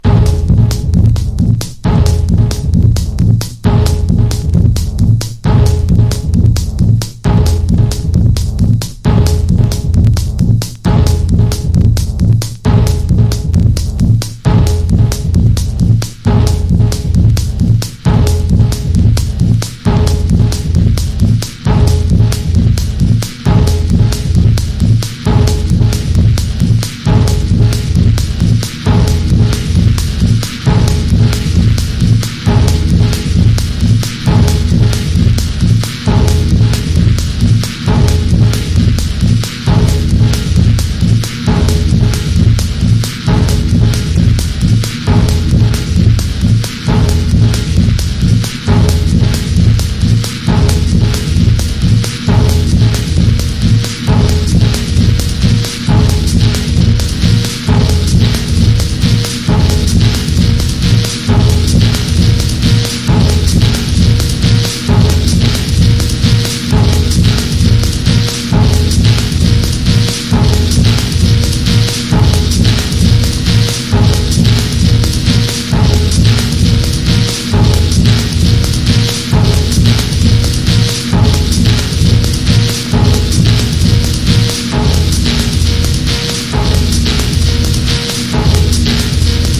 スモーキーでスペイシーなダブ・テクノ名盤！
TECHNO / DETROIT / CHICAGO# DUB / LEFTFIELD